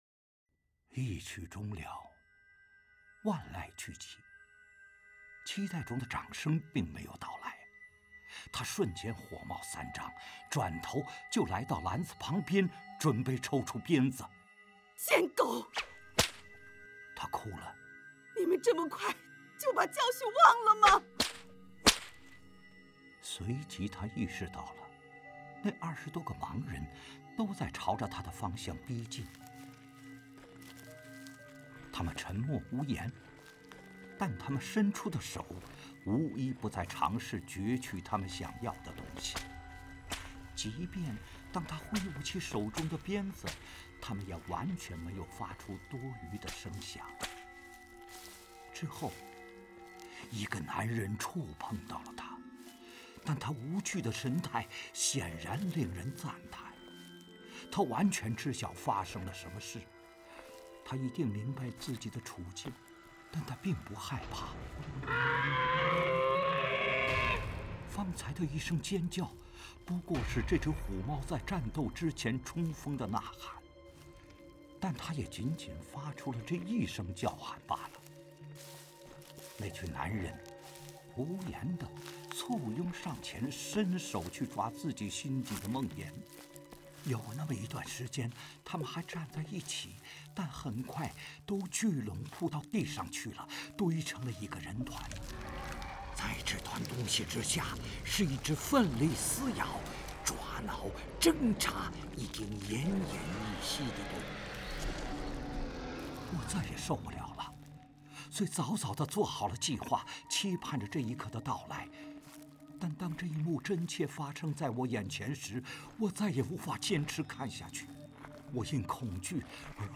《虎猫》有声书试听：